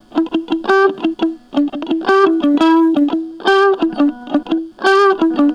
Track 02 - Guitar Lick 02.wav